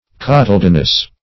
Search Result for " cotyledonous" : The Collaborative International Dictionary of English v.0.48: Cotyledonous \Cot`y*led"on*ous\ (-?s; 277), a. Of or pertaining to a cotyledon or cotyledons; having a seed lobe.
cotyledonous.mp3